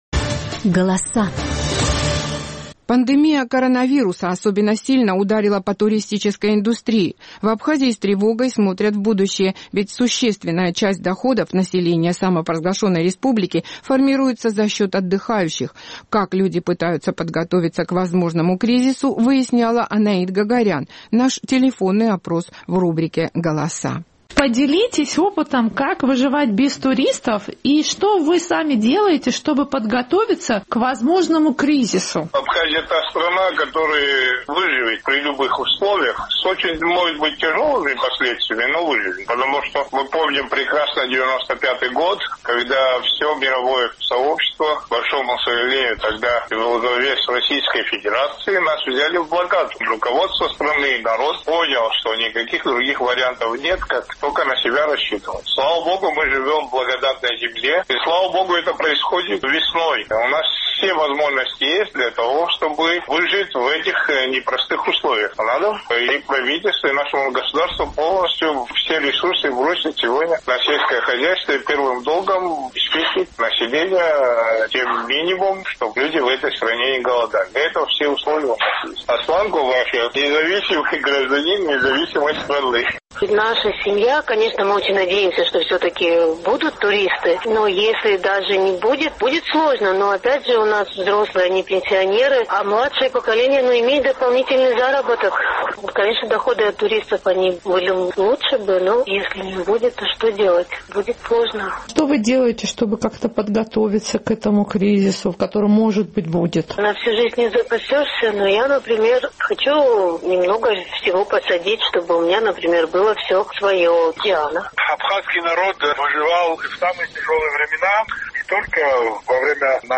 Наш сухумский корреспондент опросила пользователей социальной сети Facebook по телефону.